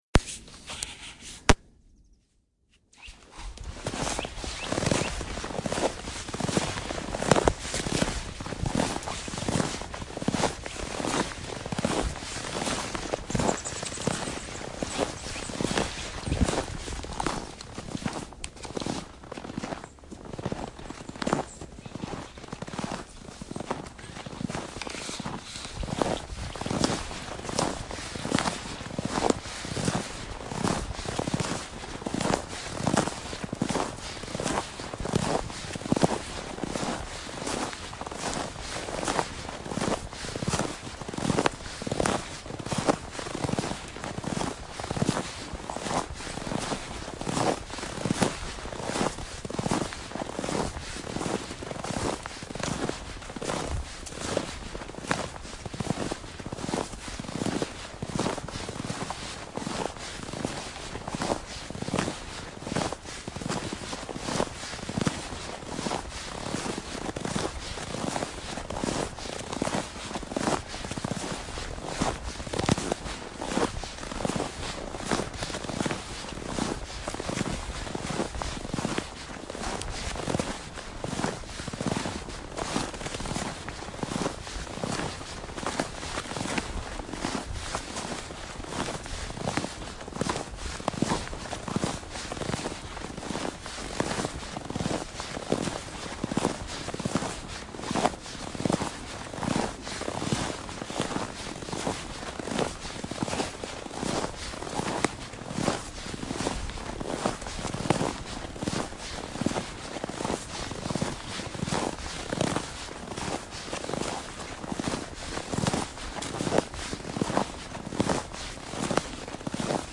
运动之声 " 泥土上的匆匆步履 - 声音 - 淘声网 - 免费音效素材资源|视频游戏配乐下载
记录某人迅速踩到泥土上的地方。